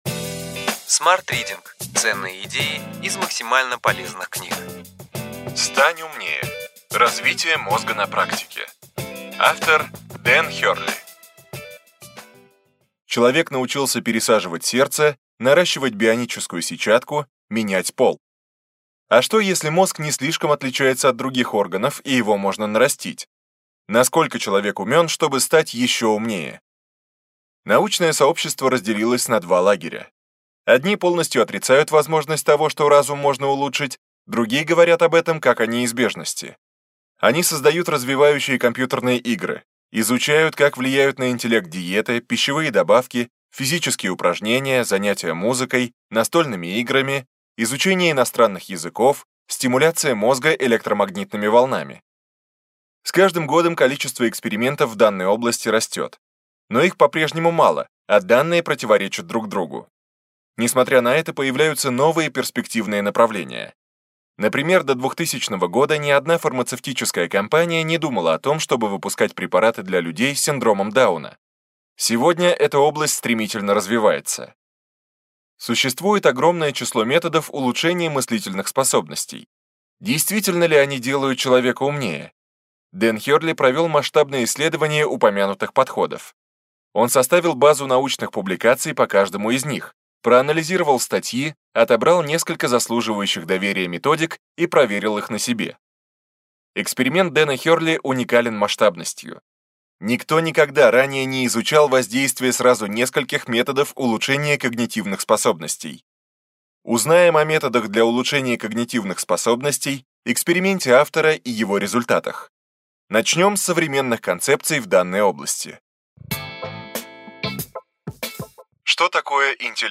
Аудиокнига Ключевые идеи книги: Стань умнее. Развитие мозга на практике.